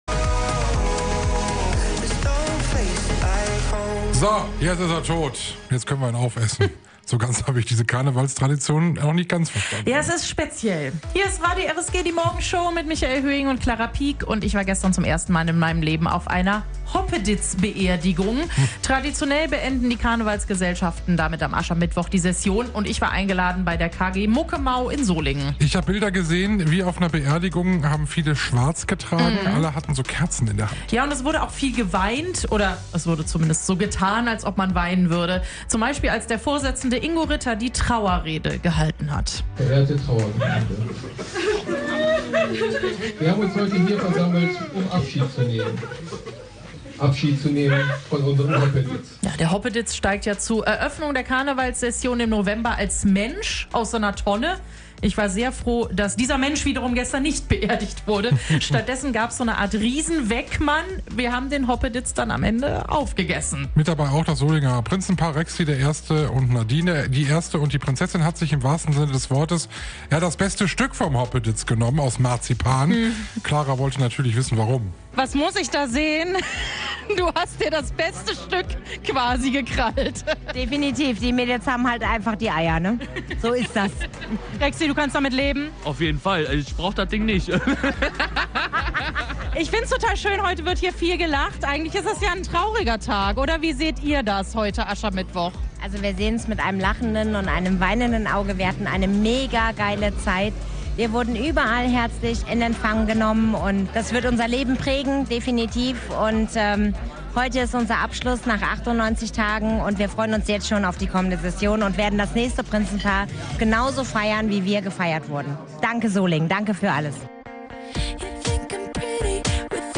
Nach dem Höhepunkt der jecken Tage, wird am Aschermittwoch ganz traditionell auch in Solingen der Hoppeditz beerdigt.